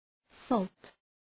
Προφορά
{sɔ:lt}